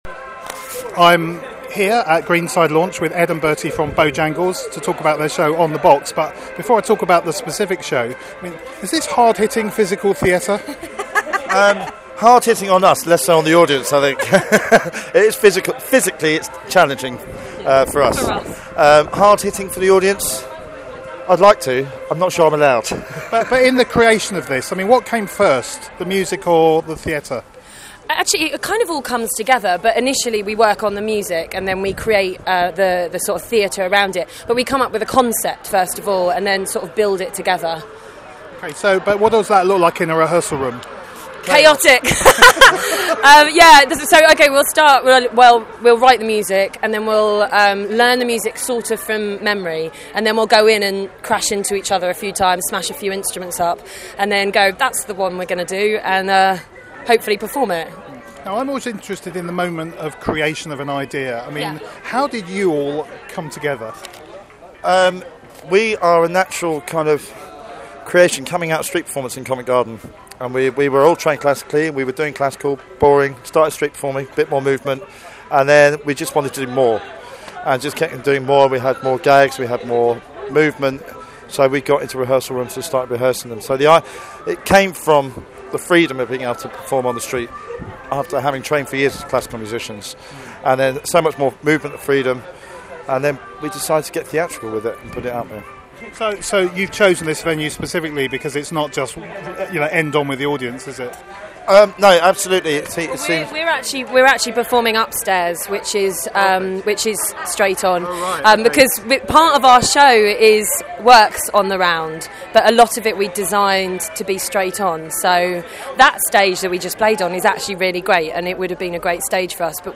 listen-to-our-interview-with-bowjangles.mp3